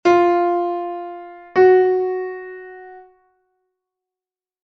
Fa Solb.2m (audio/mpeg)
2ª menor